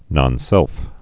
(nŏn-sĕlf)